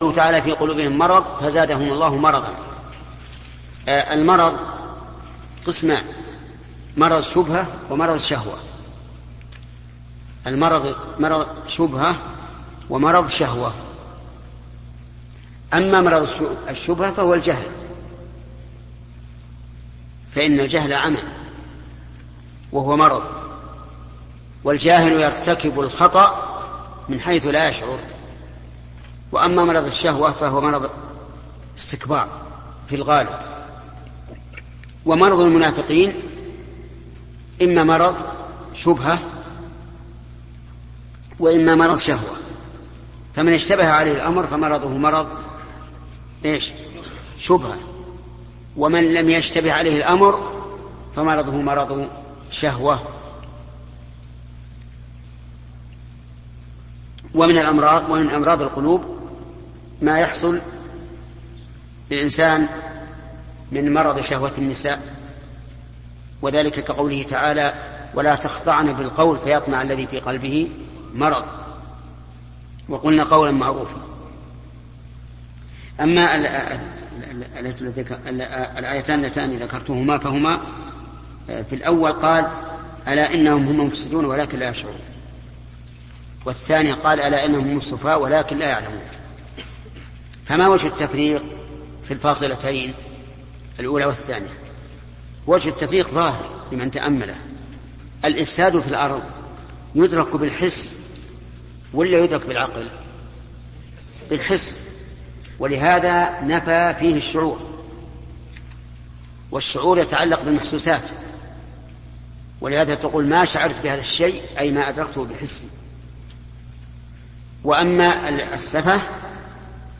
📌الشيخ محمد بن صالح العثيمين / تفسير القرآن الكريم